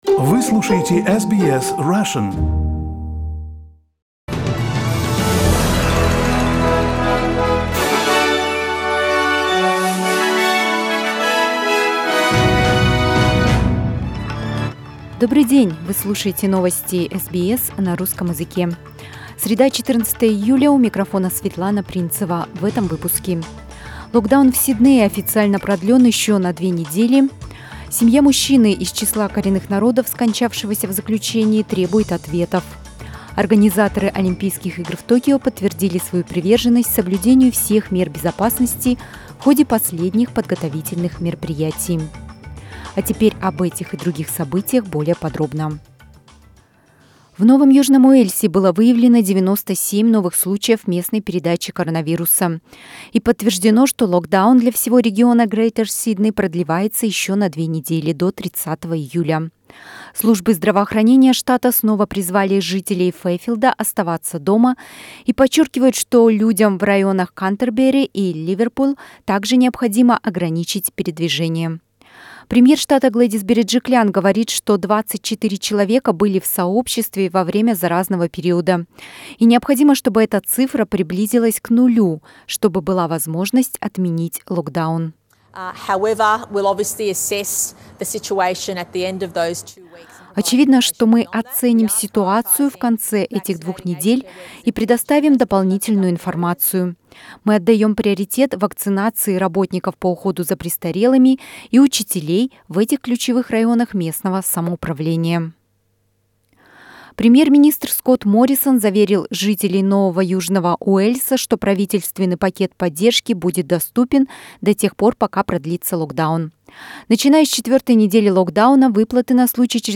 Новости SBS на русском языке - 14.07